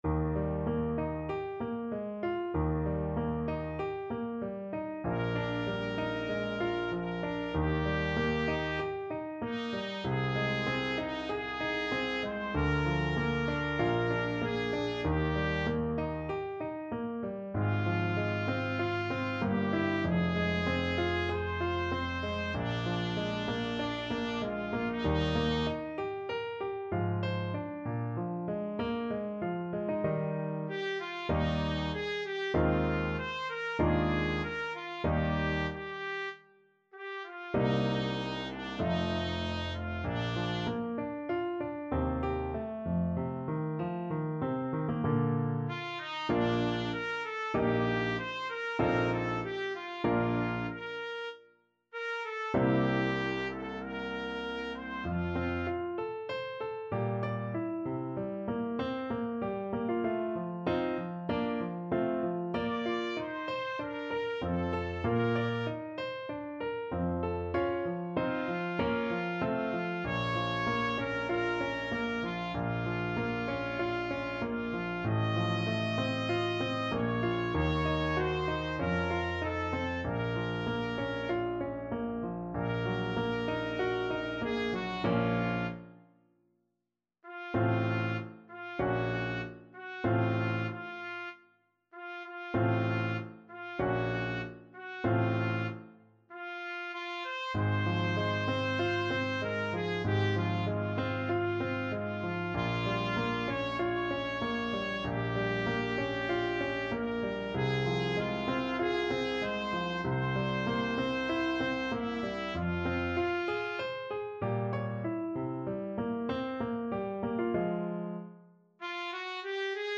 Classical Mozart, Wolfgang Amadeus Abendempfindung an Laura, K.523 Trumpet version
Trumpet
Eb major (Sounding Pitch) F major (Trumpet in Bb) (View more Eb major Music for Trumpet )
4/4 (View more 4/4 Music)
D5-Eb6
~ = 96 Andante
Classical (View more Classical Trumpet Music)